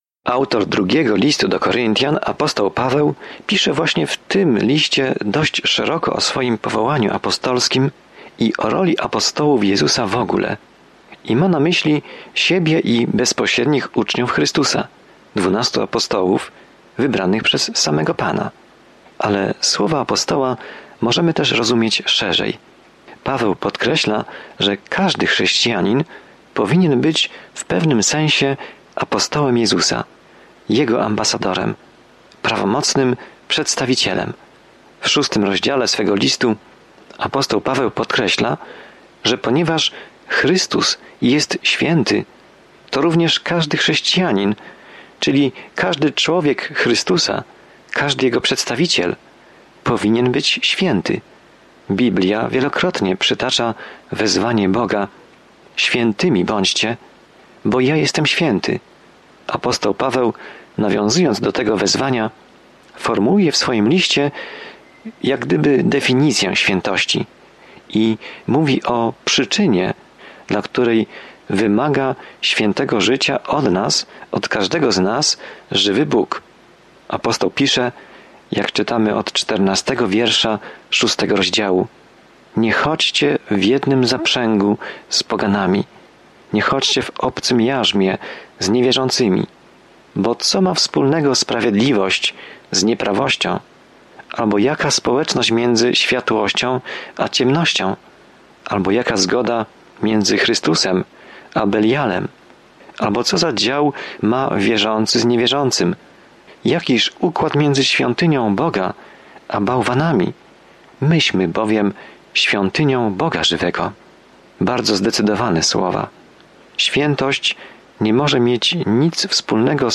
Pismo Święte 2 Koryntian 6:14-18 2 Koryntian 7:1 Dzień 11 Rozpocznij ten plan Dzień 13 O tym planie Radość relacji w Ciele Chrystusa jest podkreślona w drugim Liście do Koryntian, gdy słuchasz studium audio i czytasz wybrane wersety słowa Bożego. Codziennie podróżuj przez 2 List do Koryntian, słuchając studium audio i czytając wybrane wersety ze słowa Bożego.